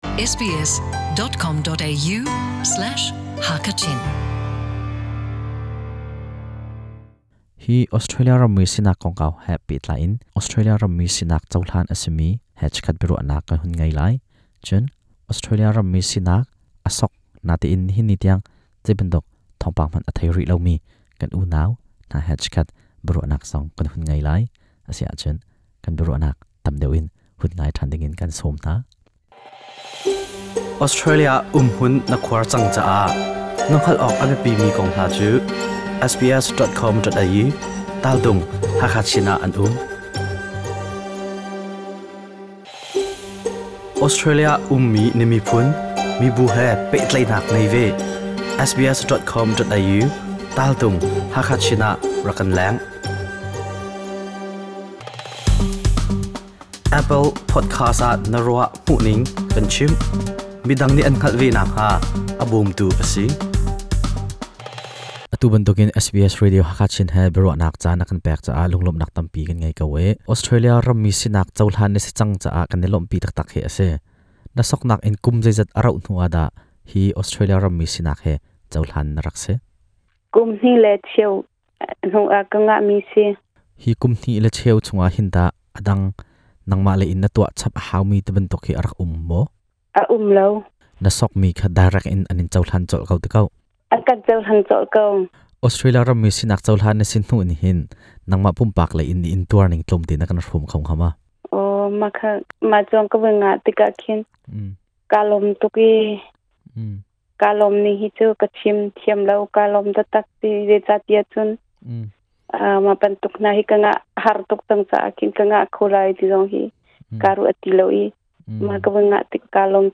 Australia Rammi Sinak (Citinzen) cu Australia ummi Chinmi, soktlak a simi zong nih an sok cio nain, nihin ni tiangah cohlan a simi hi an tlawm; cohlan rihlo mi an tam. Cutikah, a nai ah cohlan a simi he biaruahnak le cohlan rihlomi (a hngak rihkomi minung 3) hna he SBS Hakha Chin nih biaruahnak a ngeih hna.